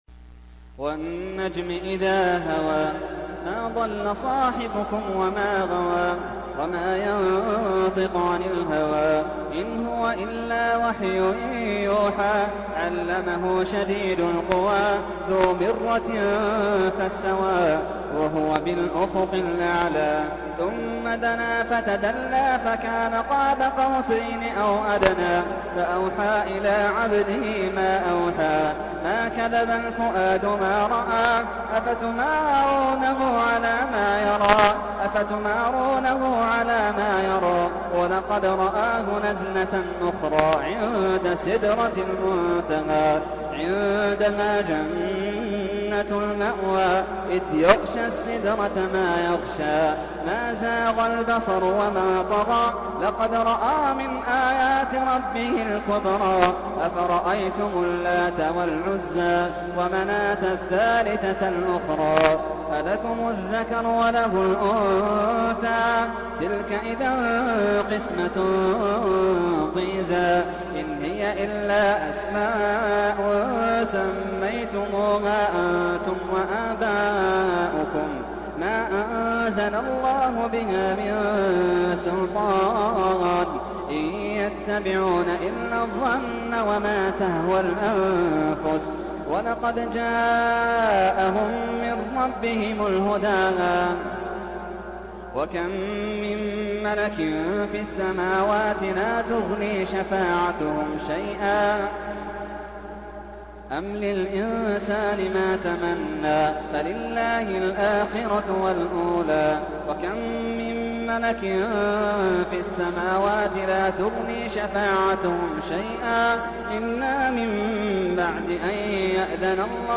تحميل : 53. سورة النجم / القارئ محمد اللحيدان / القرآن الكريم / موقع يا حسين